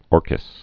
(ôrkĭs)